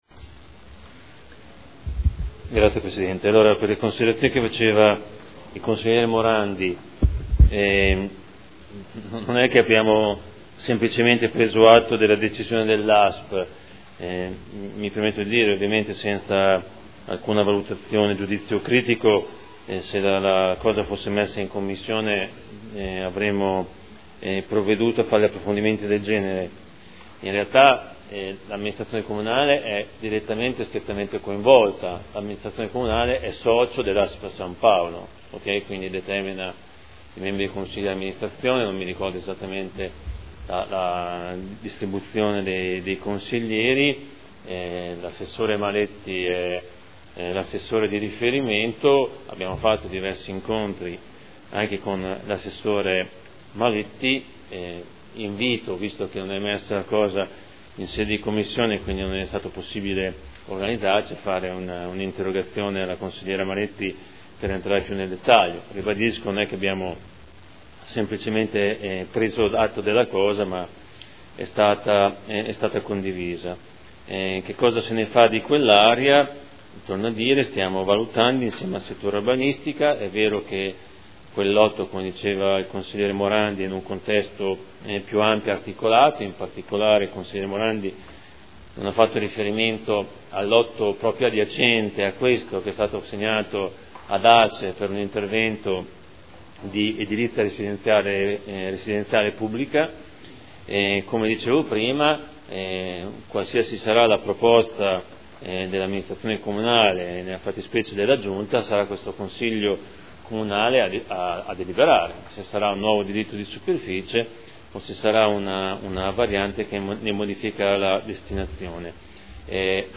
Seduta del 19 dicembre. Proposta di deliberazione: Estinzione anticipata del diritto di superficie costituito a favore di ASP San Paolo sugli immobili in Via dello Zodiaco. Dibattito